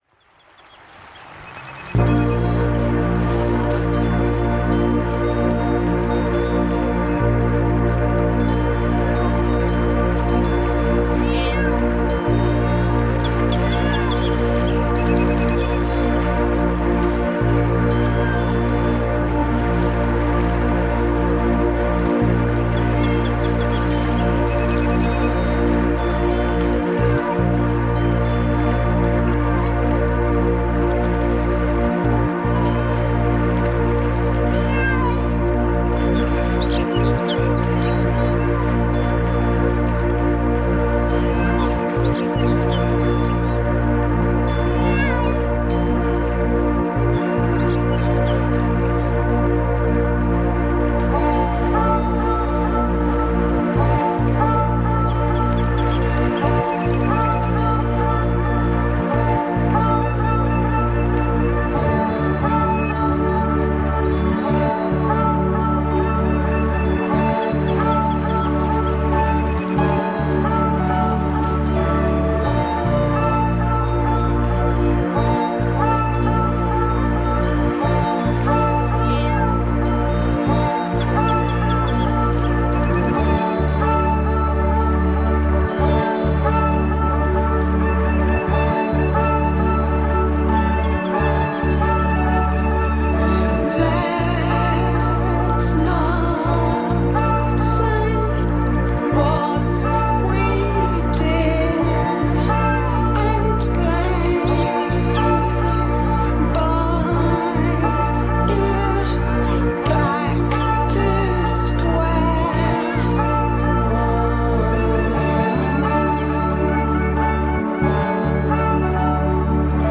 Post-Punk and Experimental Rock
Full-on Psychedelia and soul-coaxing.